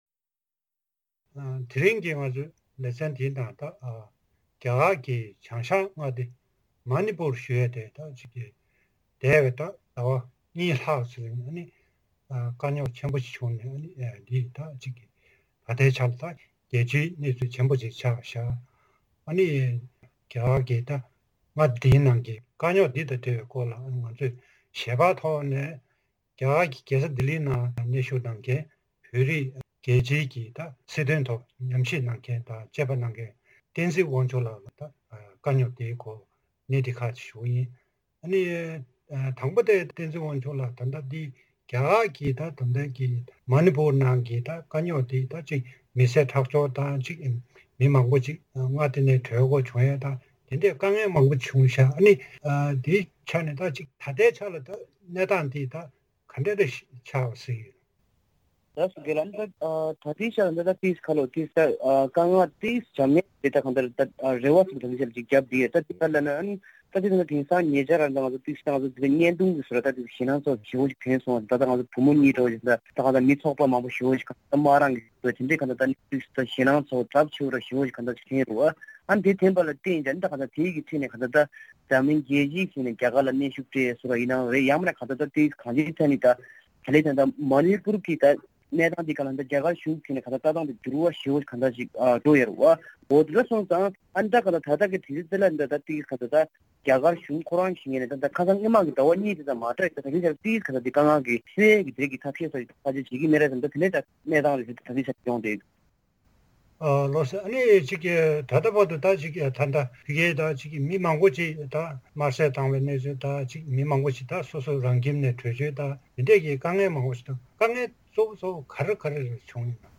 གནས་འདྲི་ཞུས་པ་ཞིག་གཤམ་ལ་གསན་རོགས་གནང་།